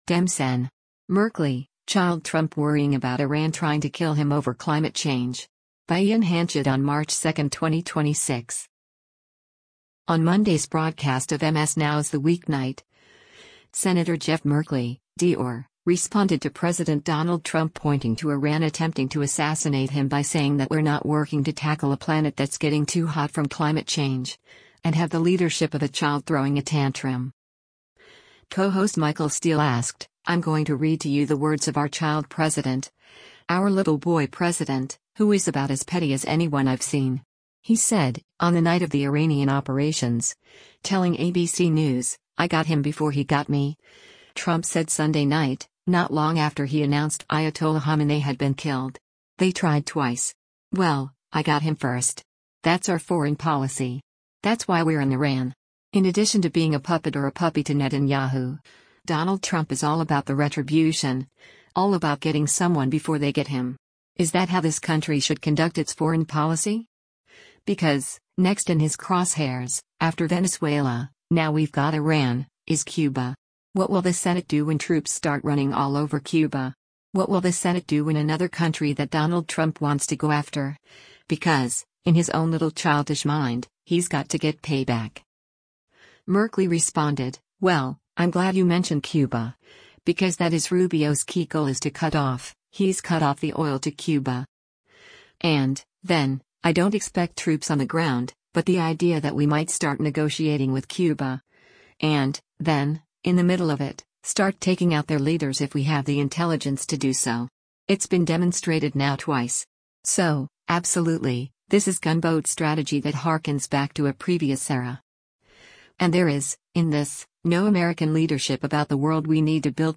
On Monday’s broadcast of MS NOW’s “The Weeknight,” Sen. Jeff Merkley (D-OR) responded to President Donald Trump pointing to Iran attempting to assassinate him by saying that we’re not working “to tackle a planet that’s getting too hot from climate change,” and have the “leadership of a child throwing a tantrum.”